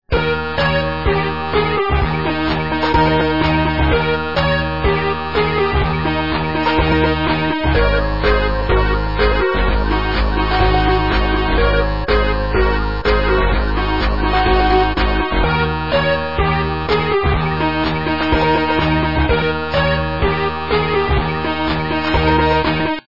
- западная эстрада